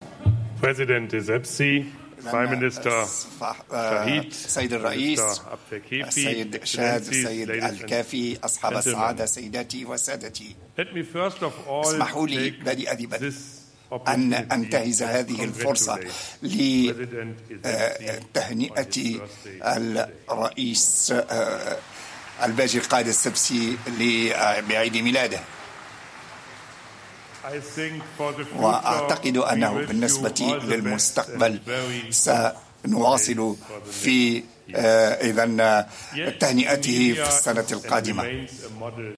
فاجأ الرئيس الألماني الأسبق كريستيان وولف الحضور في مؤتمر "تونس 2020" بتهنئة رئيس الجمهورية الباجي قايد السبسي بعيد ميلاده".
وقد رد الحاضرون وعلى رأسهم رؤساء الدول والحكومات على هذه التهنئة بالتصفيق الحار.